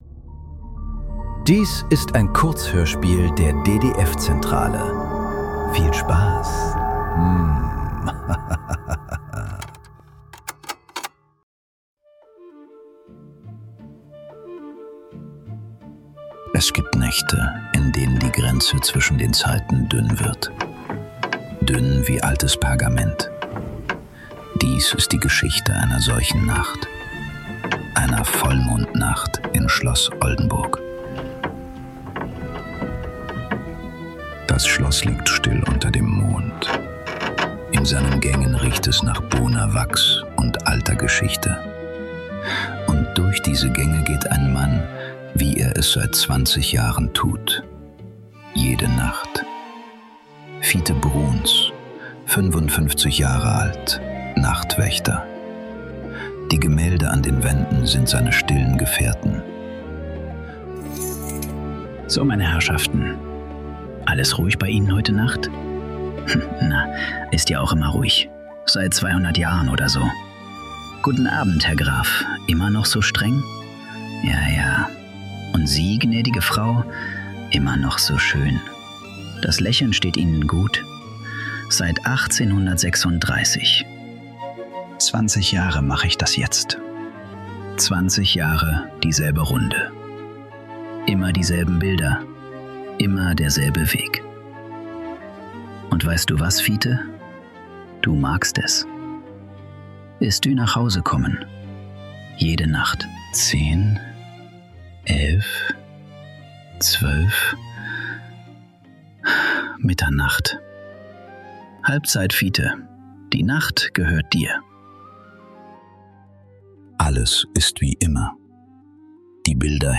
Der leere Rahmen ~ Nachklang. Kurzhörspiele.